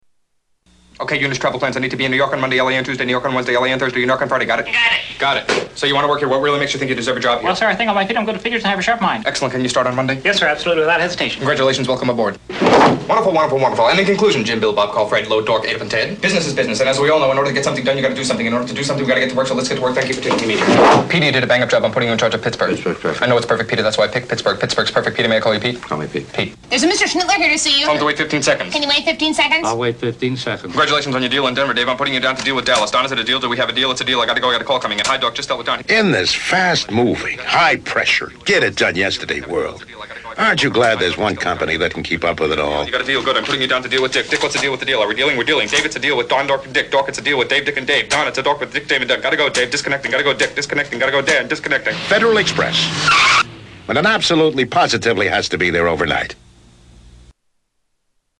FedEx commercial with John Moschitta
Tags: Media Fast Talkers Fast Tawkers Fran Capo World Record